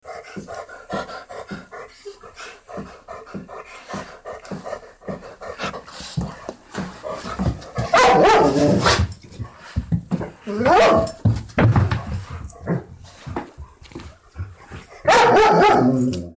Dog Panting And Barking Sound Button - Free Download & Play